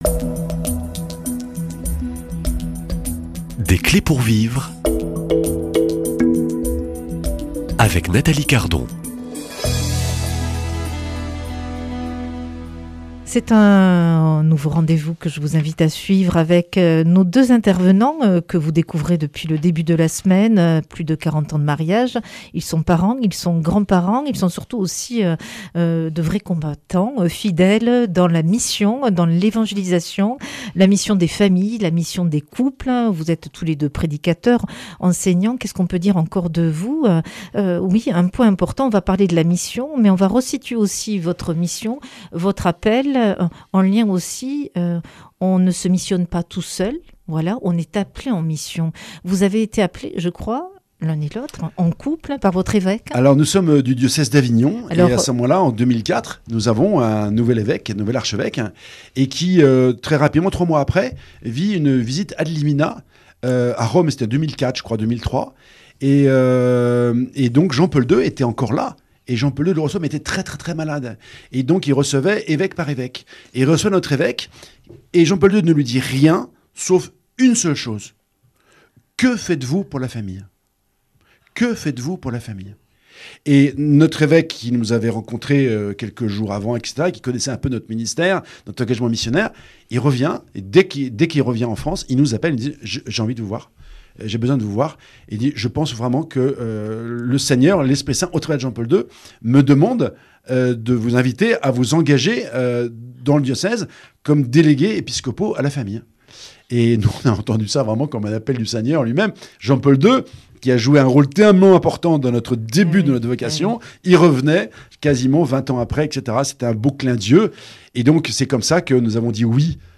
Accueil \ Emissions \ Foi \ Témoignages \ Des clés pour vivre \ Famille et mission : l’équilibre impossible ?